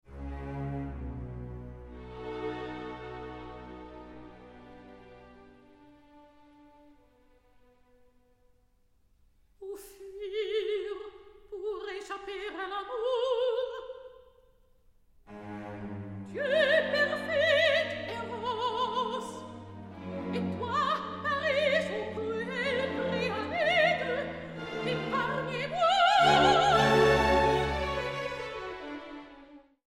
Super Audio CD
World premiere recording.